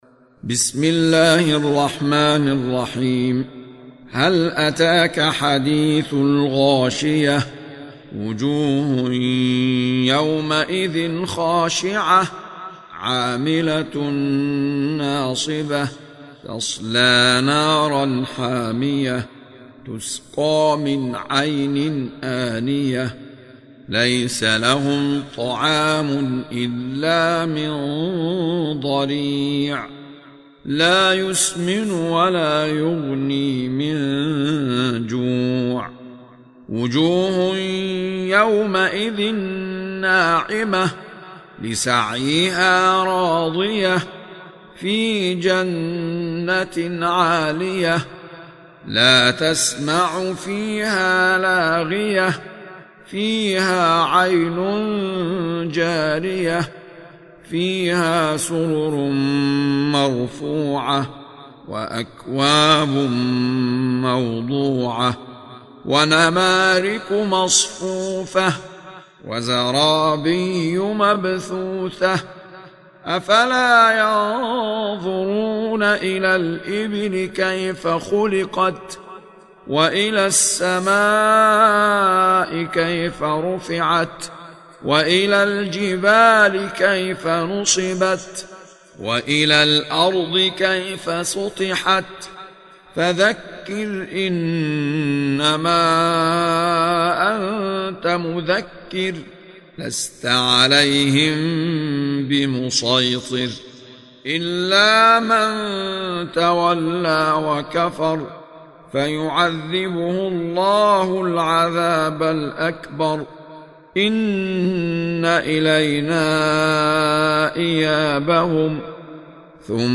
سورة الغاشية | القارئ أحمد عيسي المعصراوي